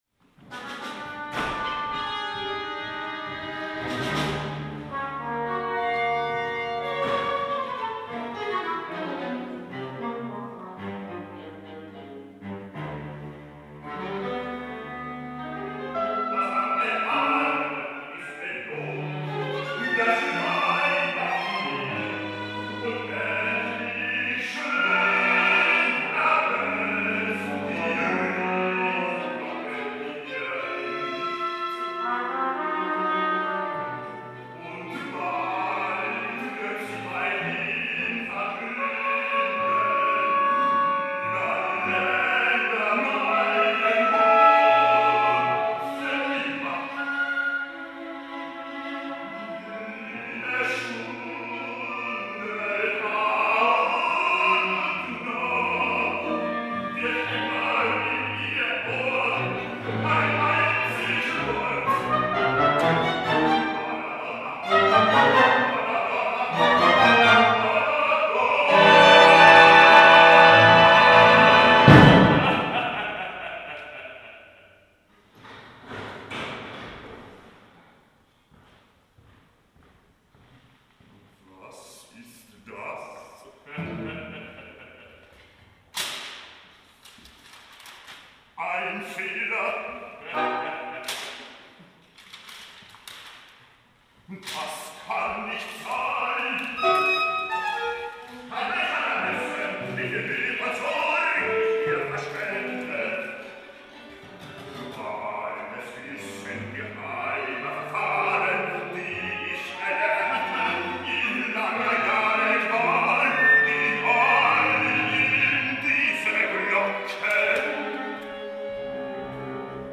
"The Belltower" Aria - 2de acte (MP-3) Een opera van Krenek uit 1957